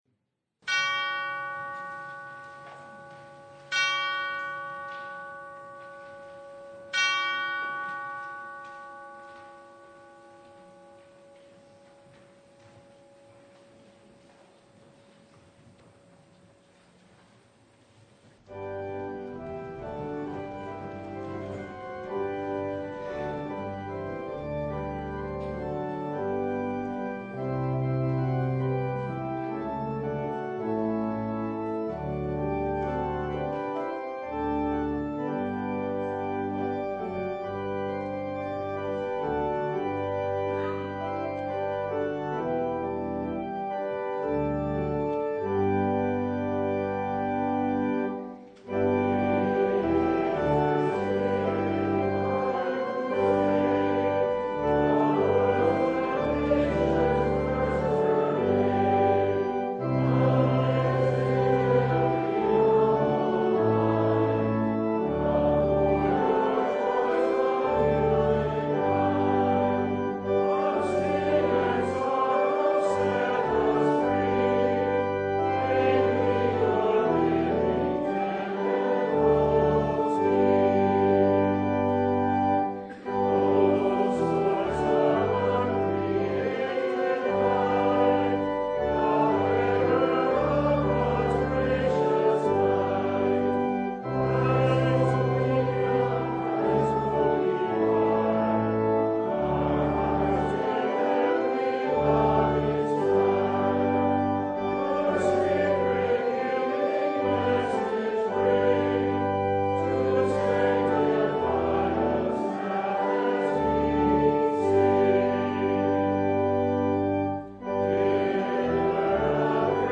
Service Type: The Feast of Pentecost
Download Files Bulletin Topics: Full Service « One in Christ Which Spirit?